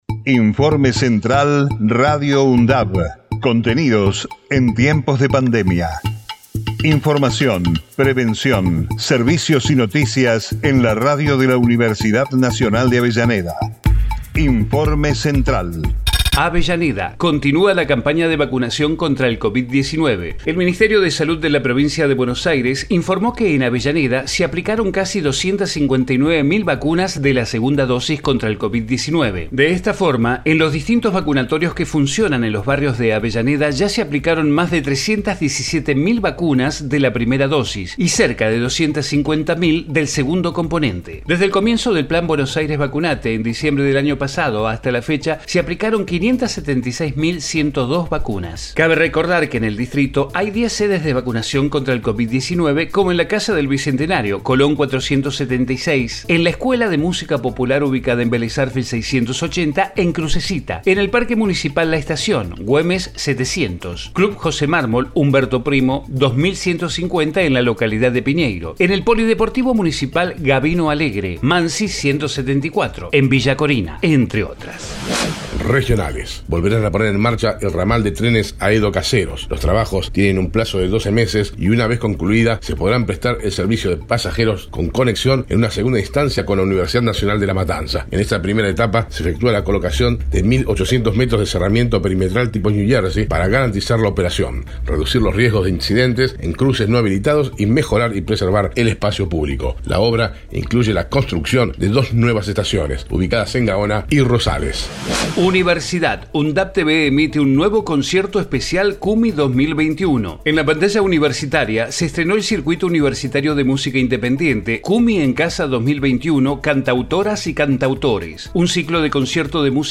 COVID-19 Informativo en emergencia 25 de OCTUBRE 2021 Texto de la nota: Informativo Radio UNDAV, contenidos en tiempos de pandemia. Información, prevención, servicios y noticias locales, regionales y universitarias.